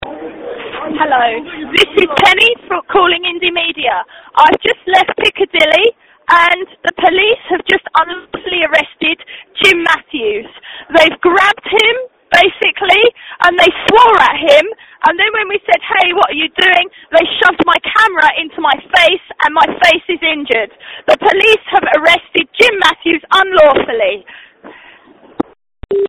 Audio report: arrest at Picadilly